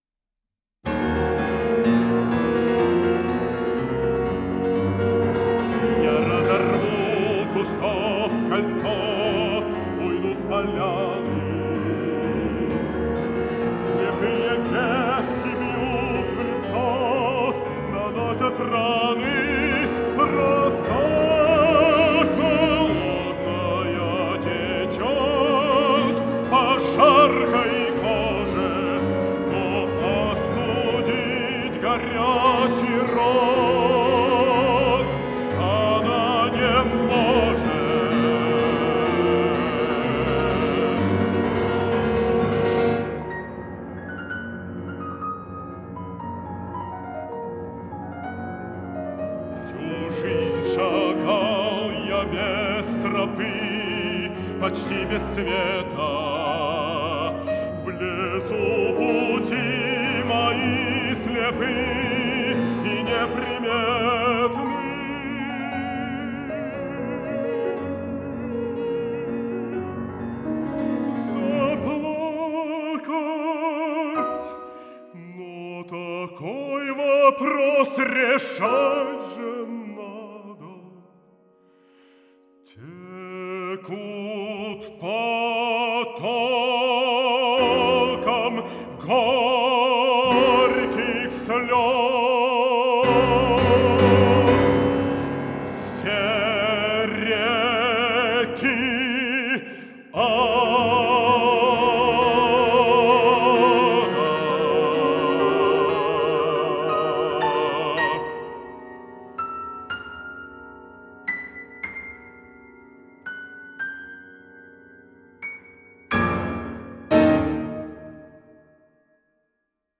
вокальный цикл для баритона и фортепиано